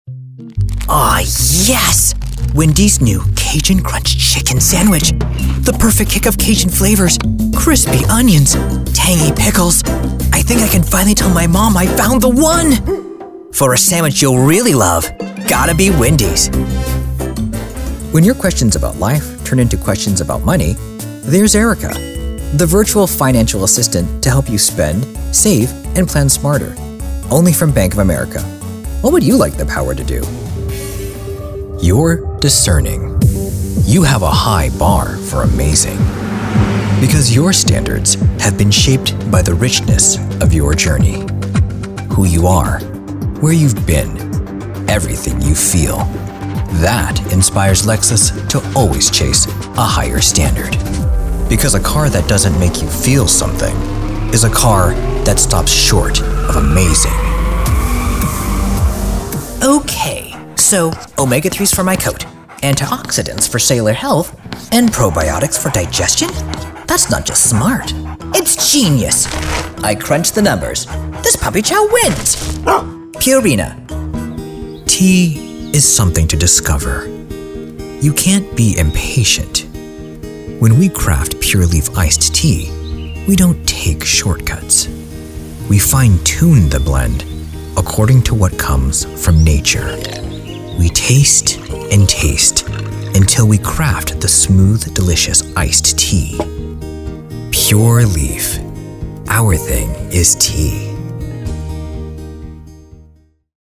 Pro Sounding Luxury, High End, Calm Voice
Commercial Demo Reel
Commercial: Educational, Easy going, Clear, Relatable, Friendly, Generous, Understandable, Energetic, Bubbly, Excited, Ecstatic, Funny, Sarcastic, Attractive, Charismatic, Charming, Kind and Persuasive.